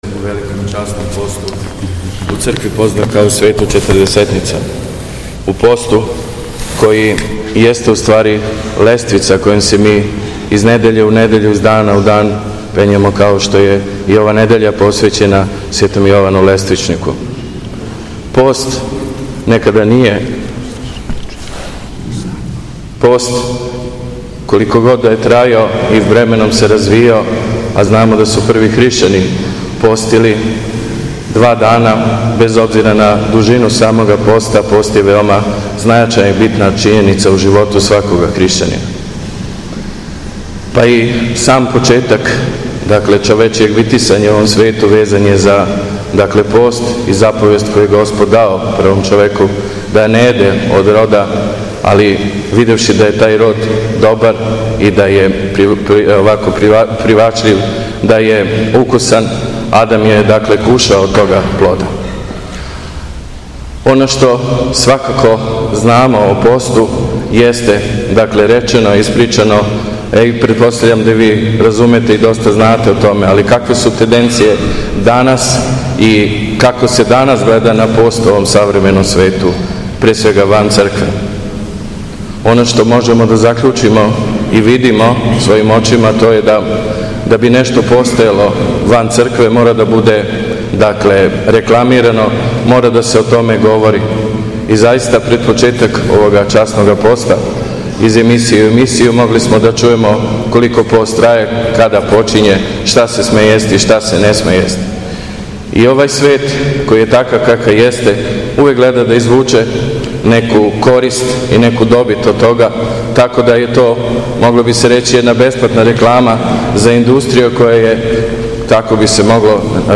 У среду 2. априла, Његово Високопреосвештенство Архиепископ крагујевачки и Митрополит шумадијски Господин Јован, служио је Литургију Пређеосвећених дарова у храму Светог Саве на Аеродрому.
Беседа